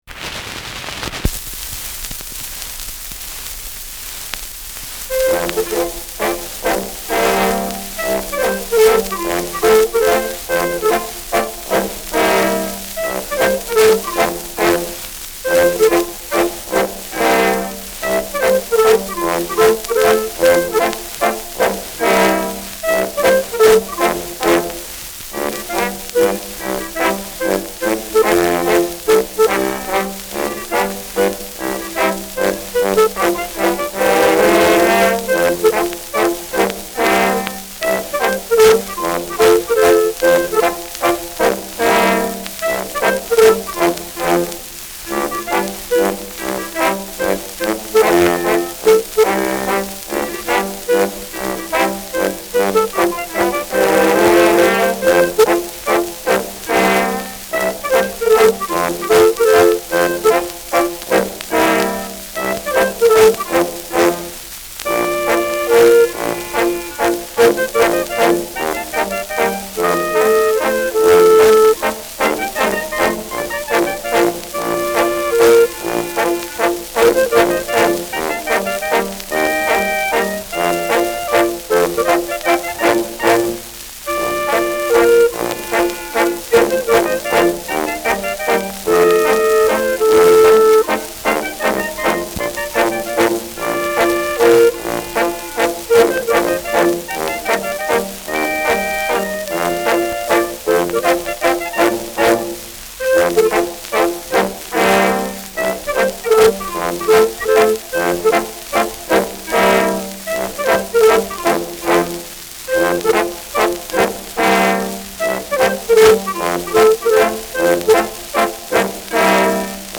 Schellackplatte
Tonrille: Kratzer 6 Uhr Leicht
Abgespielt : Vereinzelt leichtes Knacken
Boxberger Bauern-Instrumental-Quartett (Interpretation)
Folkloristisches Ensemble* FVS-00015